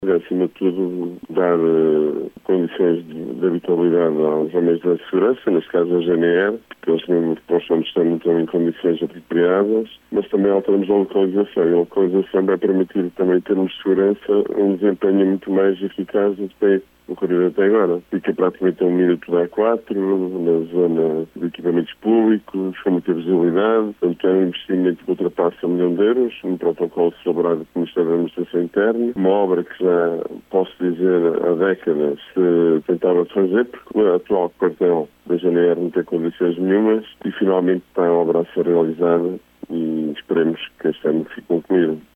Mário Artur Lopes, presidente da Câmara Municipal de Murça, destacou a importância desta empreitada, afirmando que as obras têm como objetivo “dotar o edifício de requisitos fundamentais para uma maior eficiência e bom desempenho da GNR na sua missão policial: